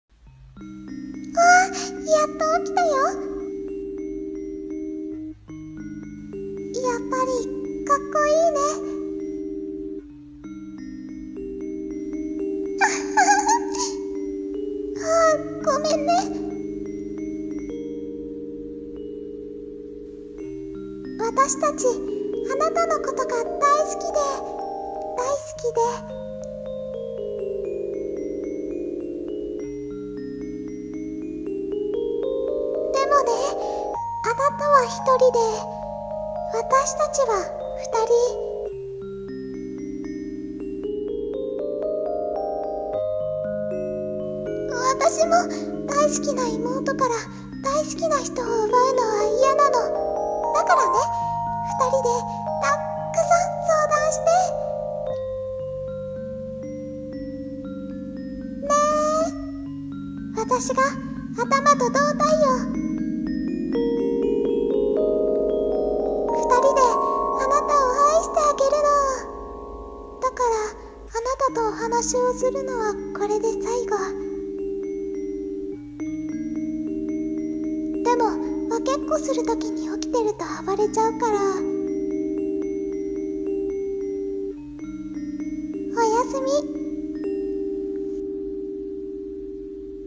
【声劇台本】ヤンデレ双子【ヤンデレ】